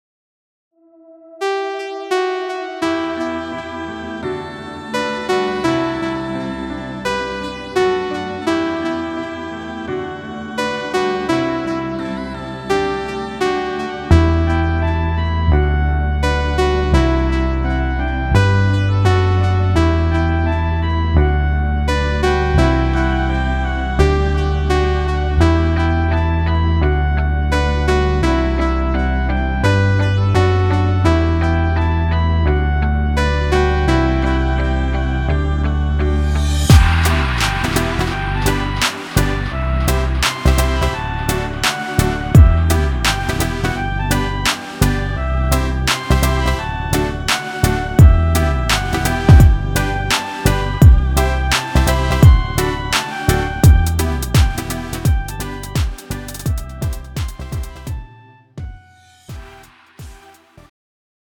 미리듣기
음정 원키
장르 pop 구분 Pro MR